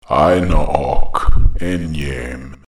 eye-naw-awk    ehn-yaym